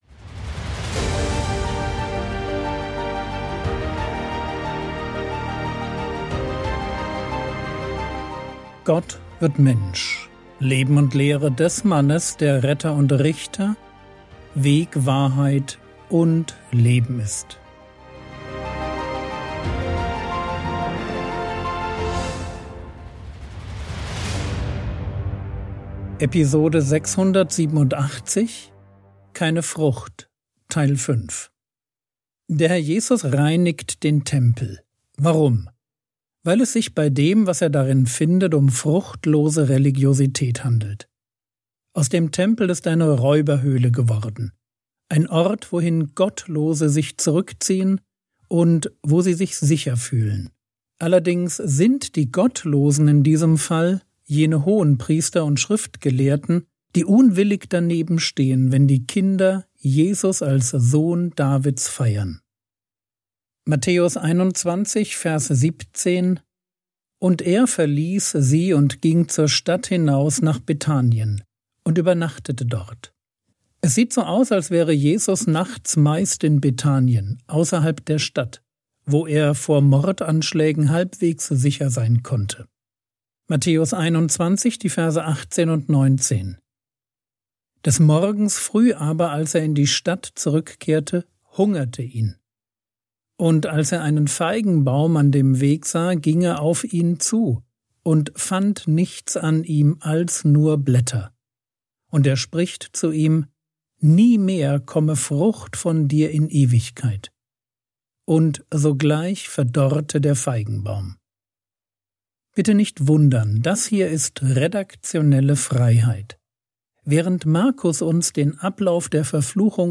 Episode 687 | Jesu Leben und Lehre ~ Frogwords Mini-Predigt Podcast